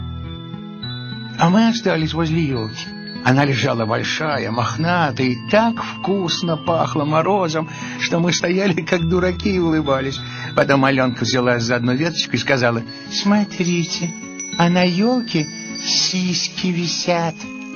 аудиозапись – отрывок из произведения Драгунского. “Заколдованная буква”.